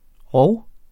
rov 2 substantiv, intetkøn Udtale [ ˈʁɒw ]